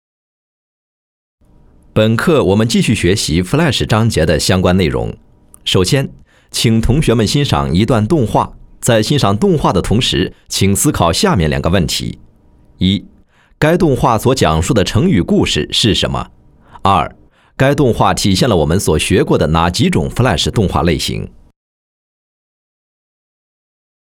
多媒体配音